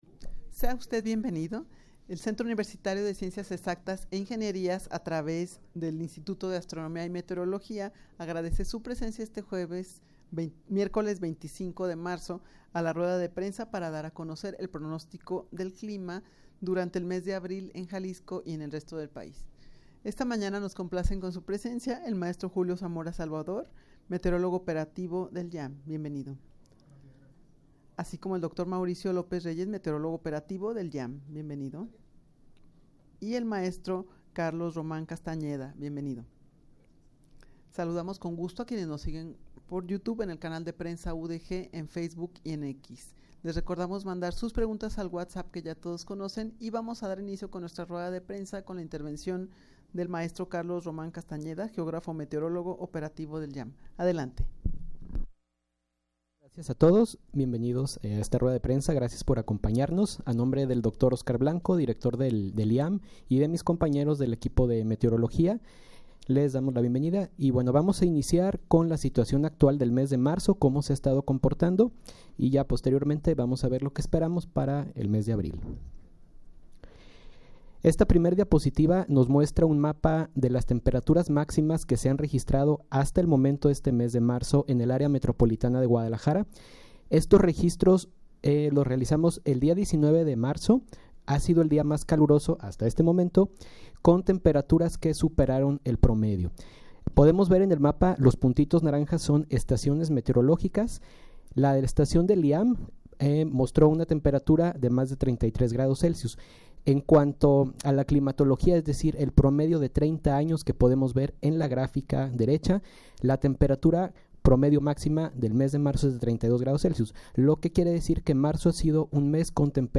Audio de la Rueda de Prensa
rueda-de-prensa-para-dar-a-conocer-el-pronostico-del-clima-durante-abril-en-jalisco-y-en-el-resto-del-pais.mp3